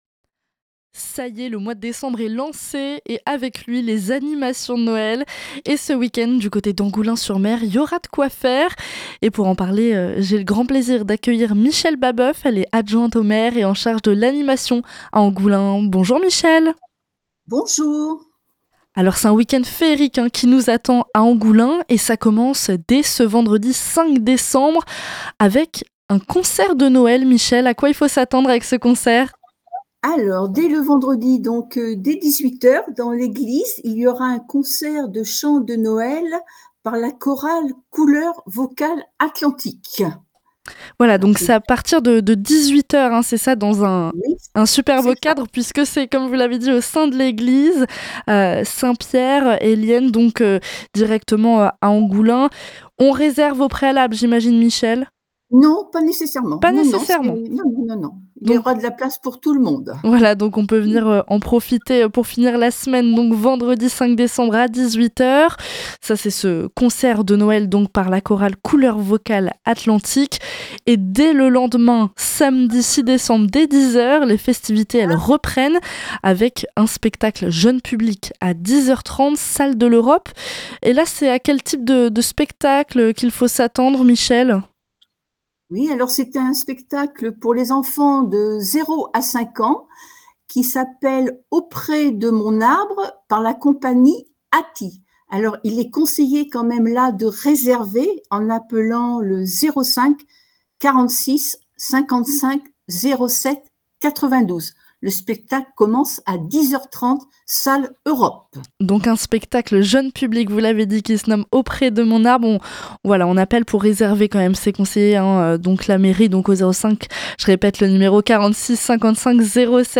Pour en parler j’ai le plaisir d’accueillir Mme Michelle BABEUF, adjointe au maire et en charge de l’animation à Angoulins.
L’interview est à retrouver ci-dessous.